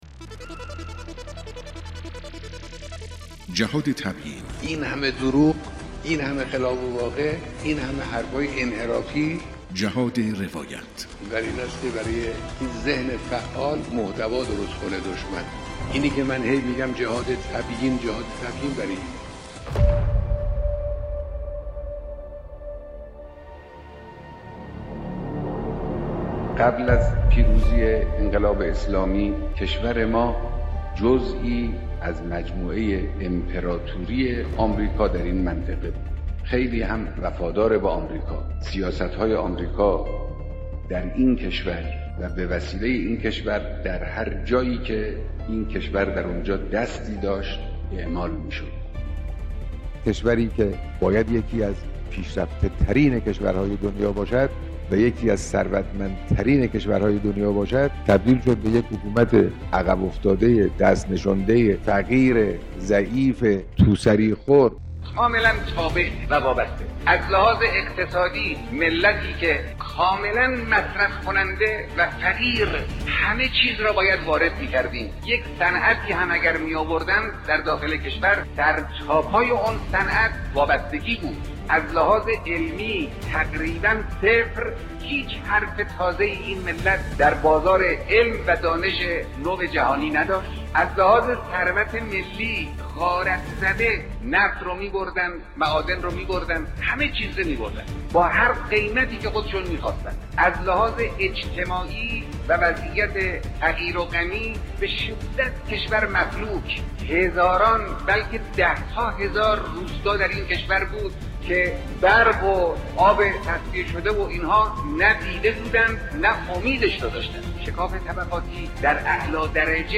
سخنان مقام معظم رهبری(حفظه‌الله)